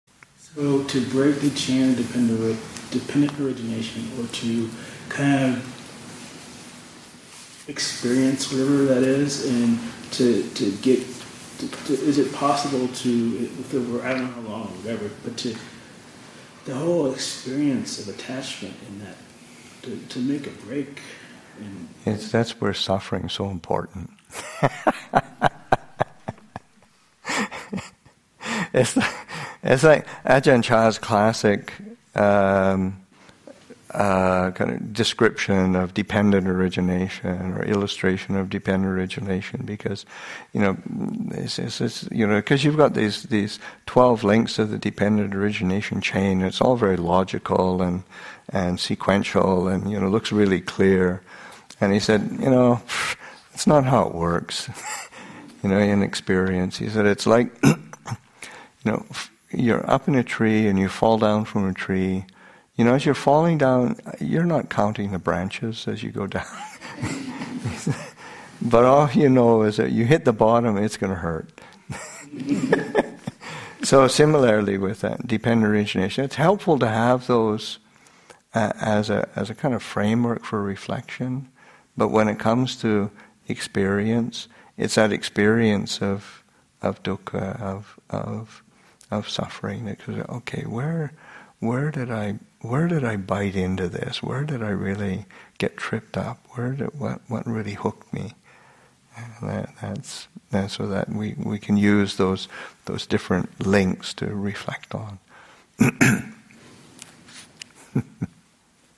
Abhayagiri Buddhist Monastery in Redwood Valley, California and online